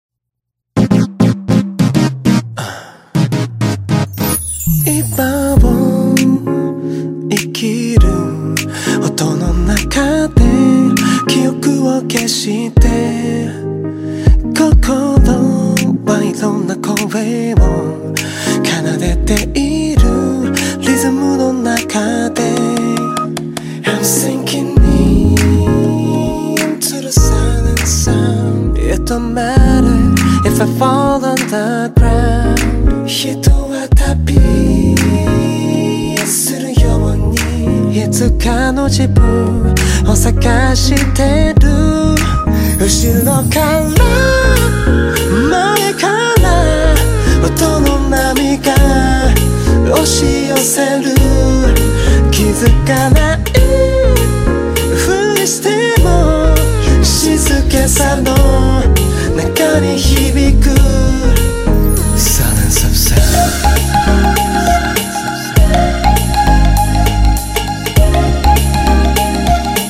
R&Bからジャズまで幅広い音楽的要素を持ち